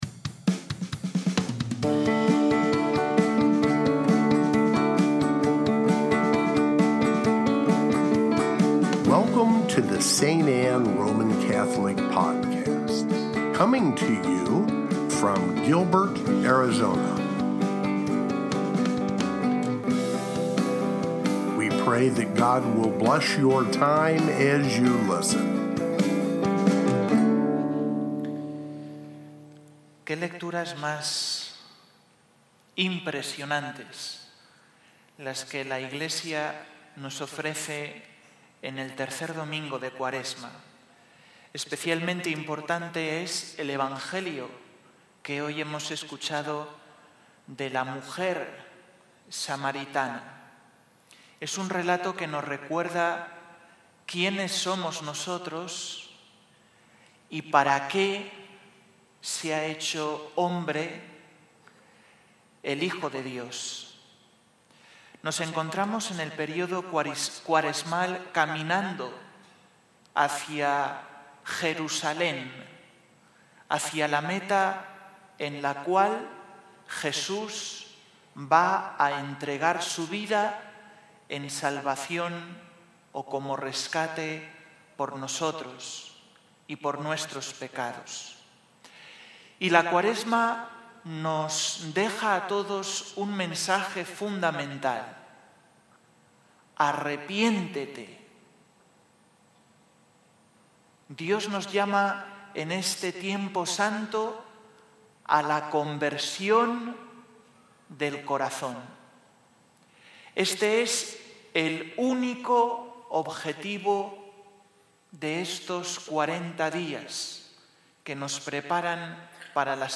III Domingo de Cuaresma (Homilia)